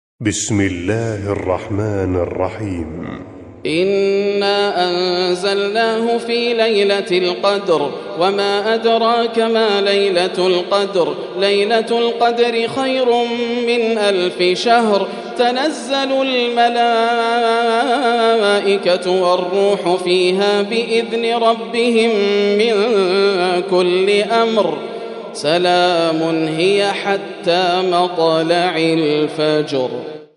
🌙🕌•||تلاوة مسائية||•🕌🌙
🔸القارئ : ياسر الدوسري🔸